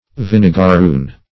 Vinegarroon \Vin`e*gar*roon"\, n. [Cf. Sp. vinagre vinegar.]